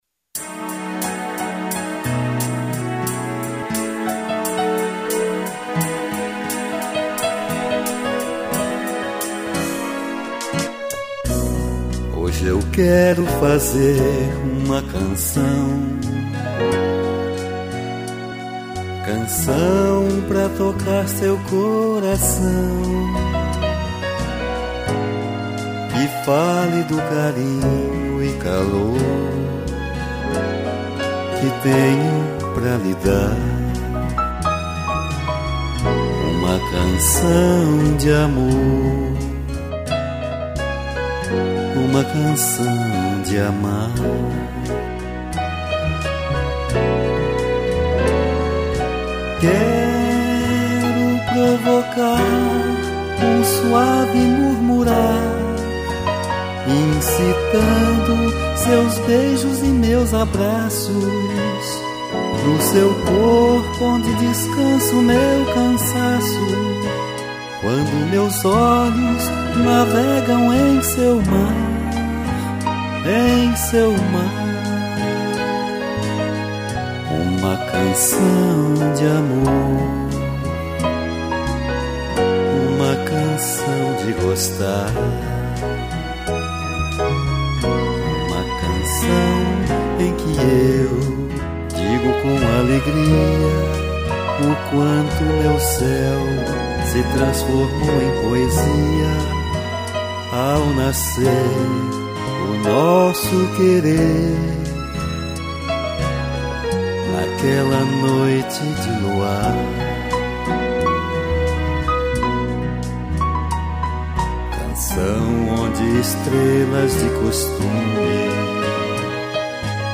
piano e strings